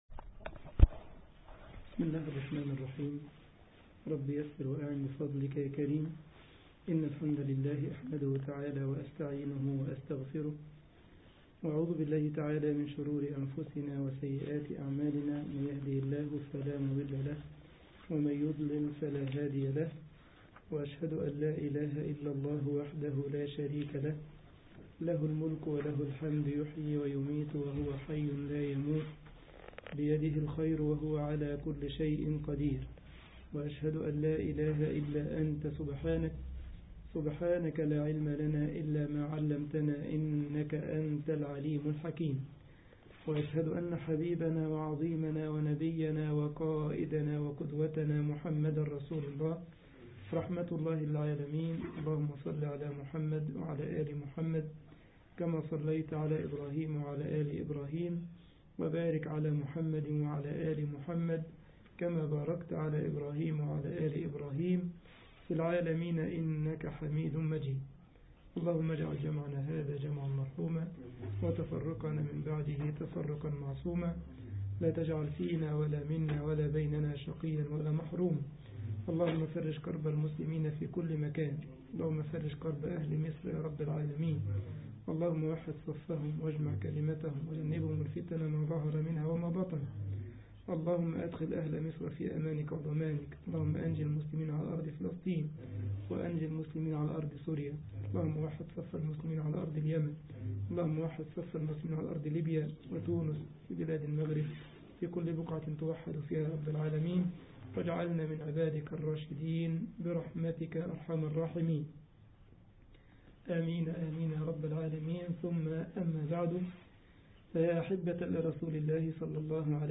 مصلى جامعة السارلند ـ ألمانيا